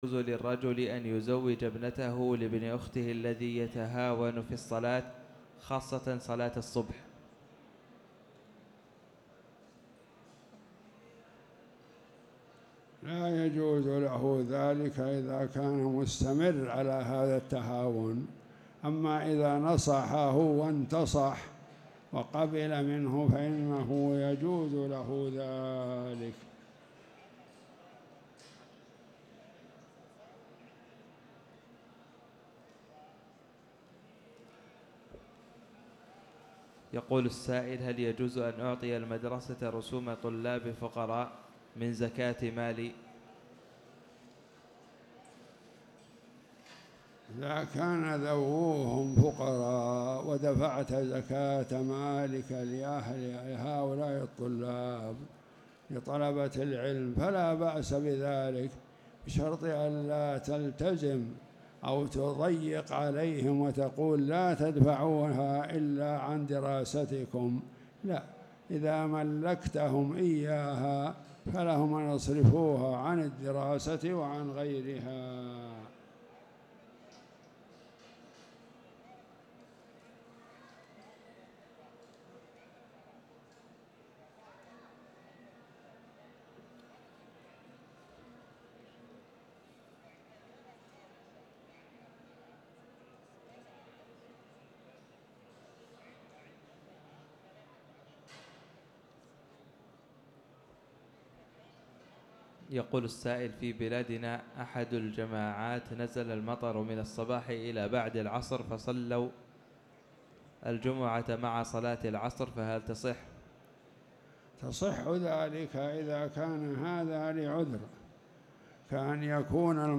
تاريخ النشر ١٨ ربيع الأول ١٤٣٨ هـ المكان: المسجد الحرام الشيخ